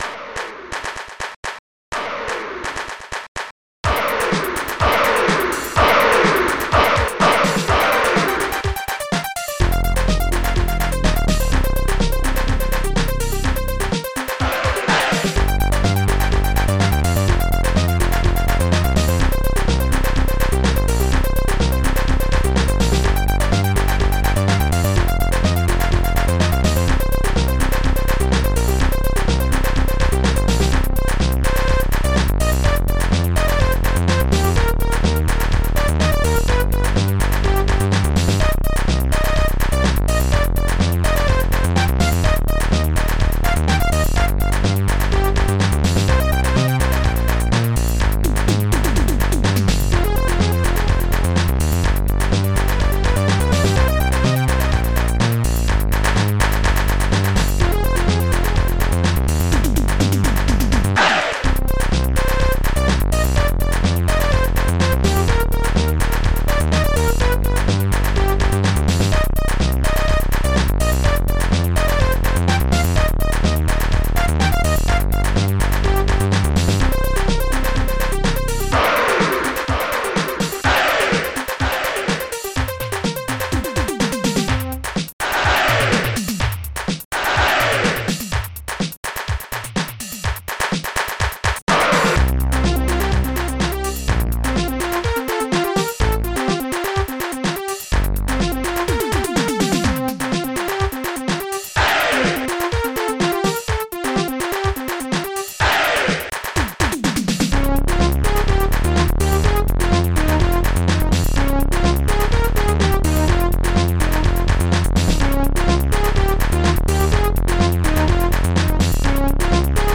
Protracker Module
hi-hat closed claps kick snare polybass hi-hat open synbrass electro-tom